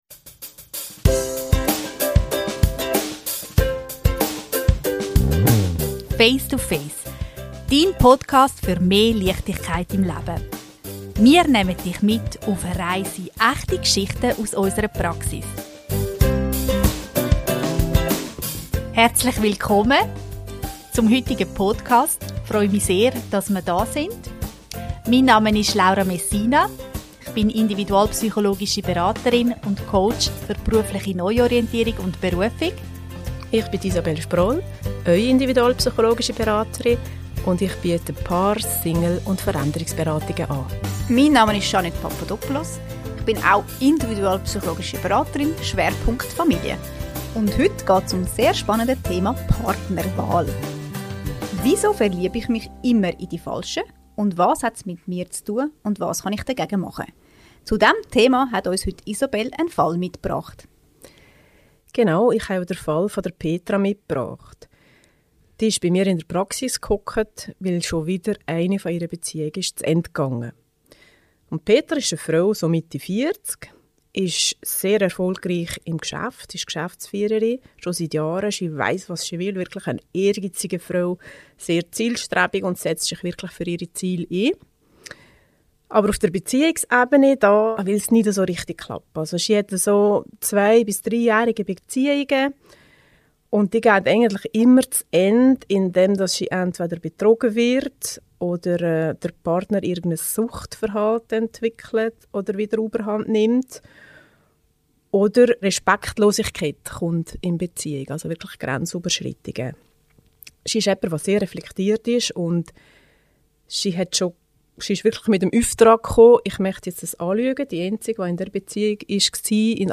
In dieser Folge sprechen die drei individualpsychologischen Beraterinnen